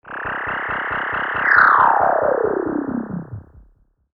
Filtered Feedback 08.wav